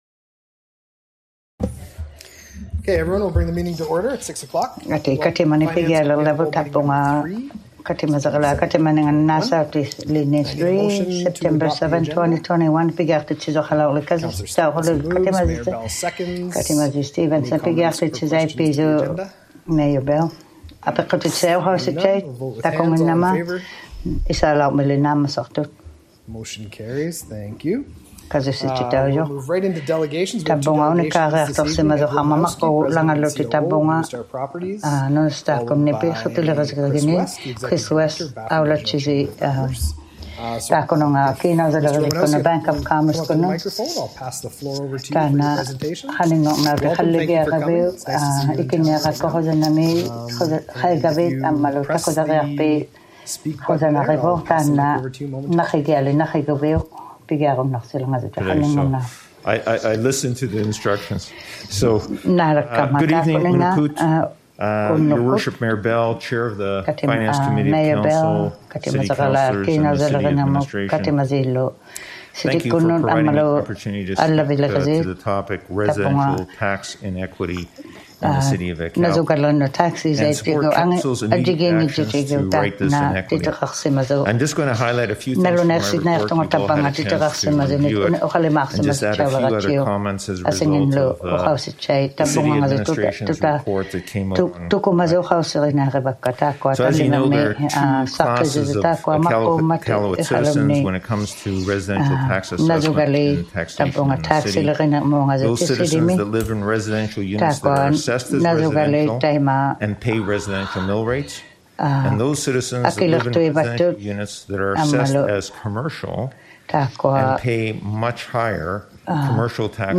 ᑮᓇᐅᔭᓕᕆᓂᕐᒥᑦ ᑲᑎᒪᔨᕋᓛᑦ ᑲᑎᒪᓂᖓᑦ #03 Finance Committee of the Whole Meeting # 03 | City of Iqaluit